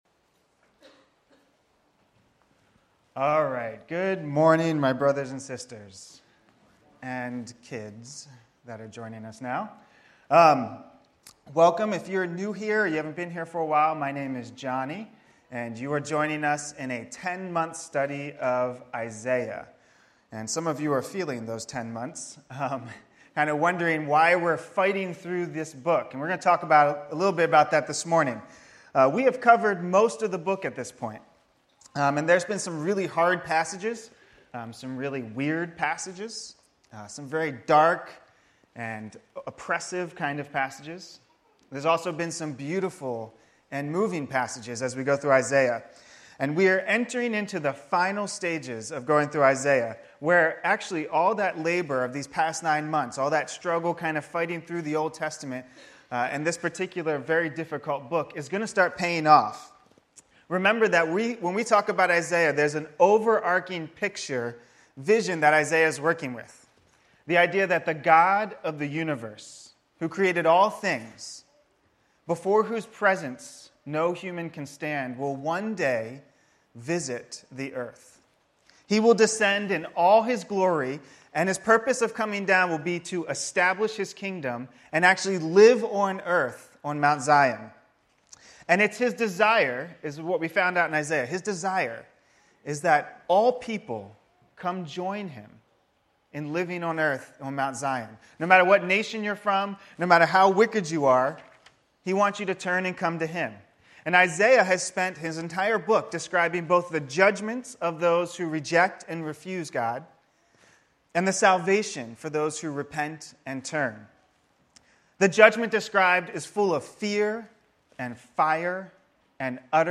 Sermons | Anchor Community Church